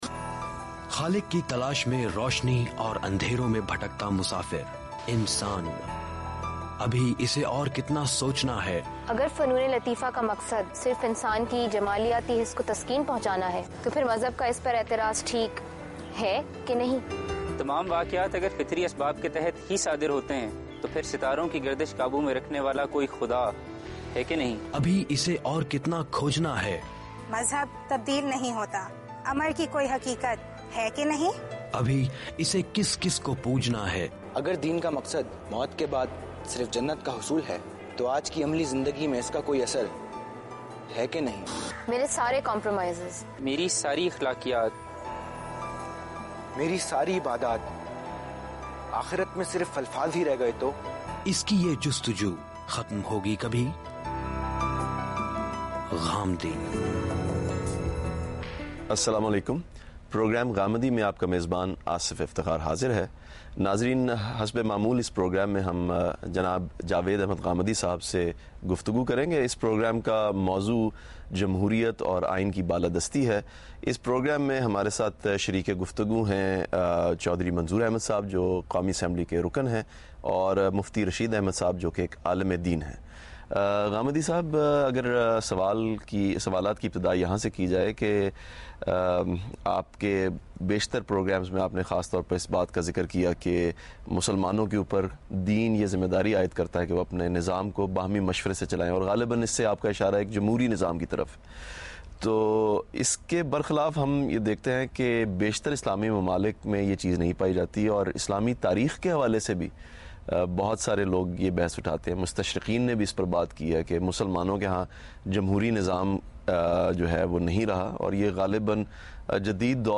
Questions and Answers - Islam and Democracy asked by todays youth and satisfying answers by Javed Ahmad Ghamidi.